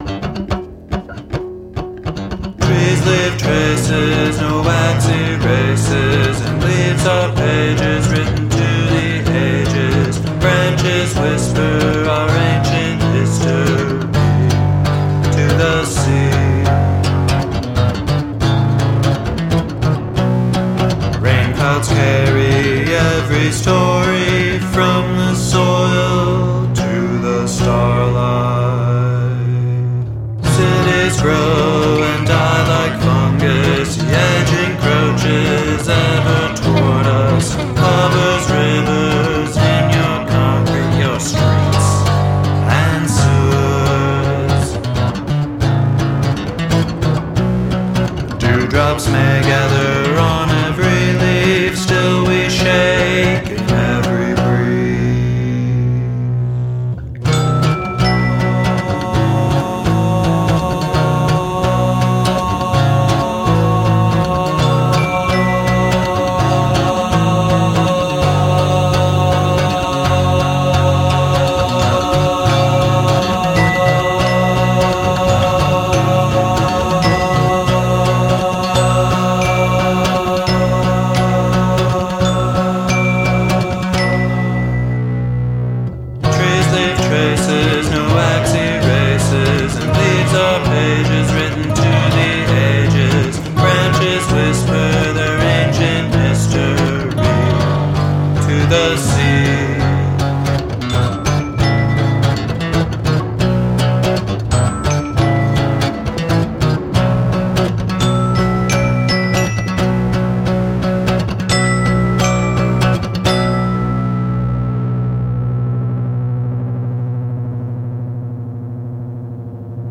dreamy soundscapes and post-rock spaces